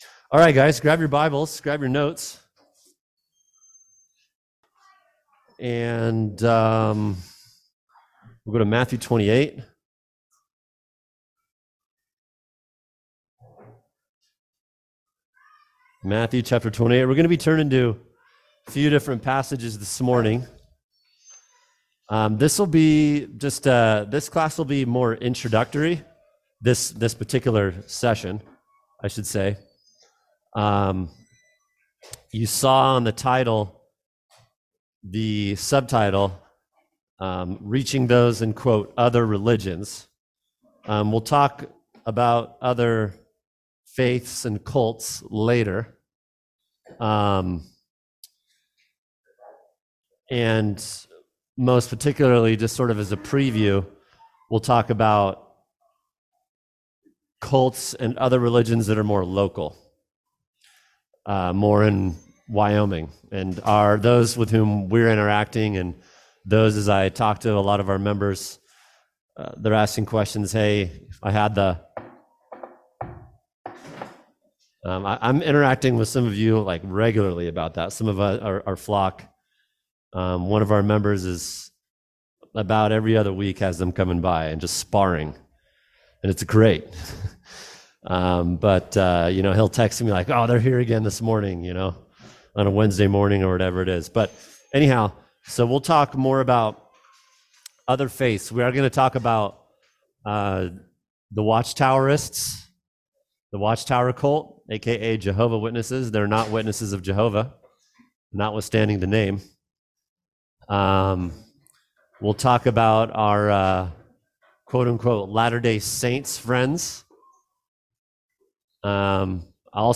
Download audio here (please note, audio quality decreases around minute 44)